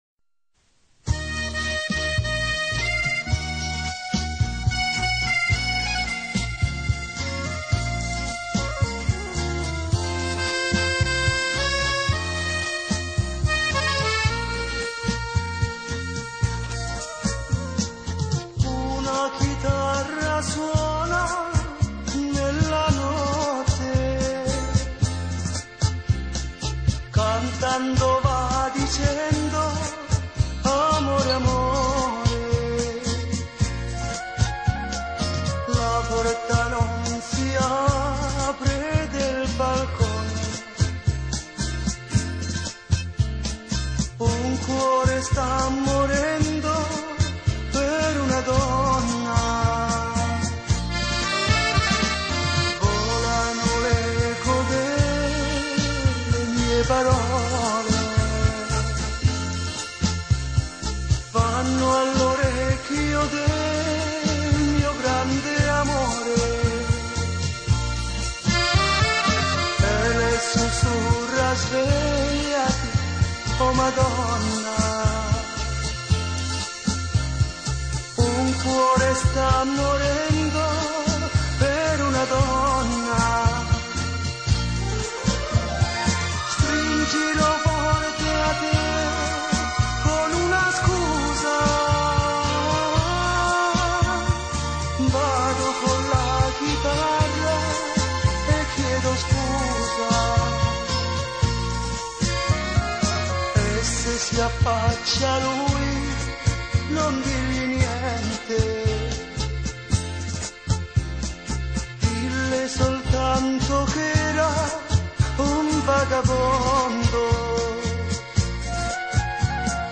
Красивая итальянская песня